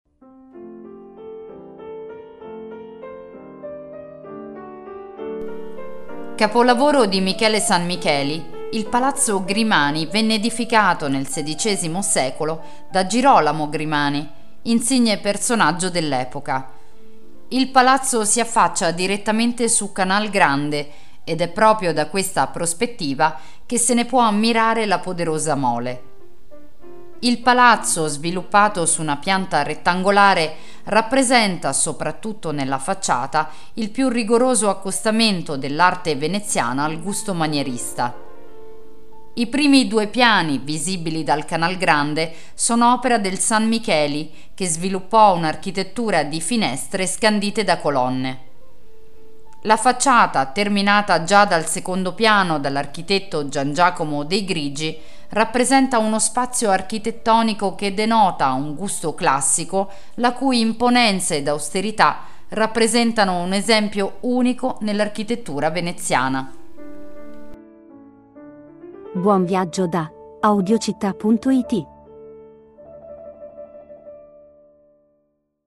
Audioguida Venezia - Palazzo Grimani - Audiocittà